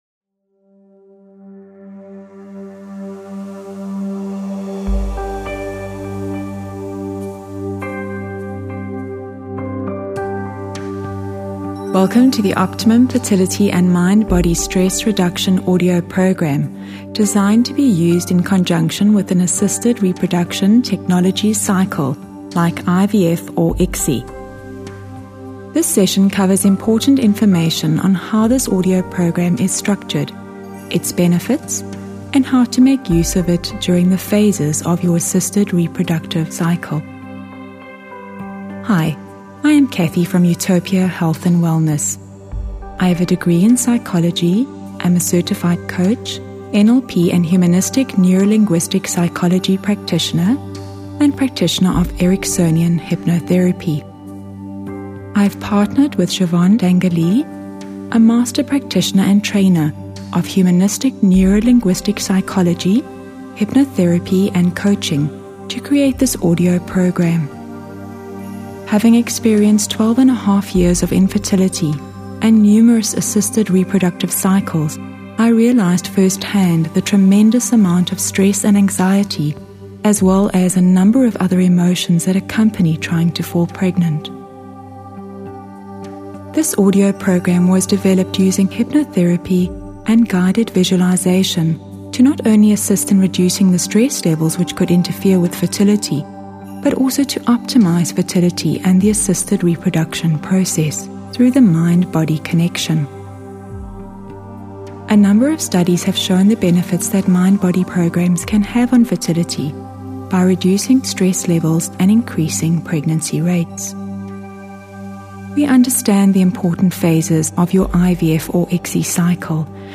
This audio programme makes use of tranquil music, enhanced with audio technologies, specifically designed to facilitate optimal states of receptivity and relaxation.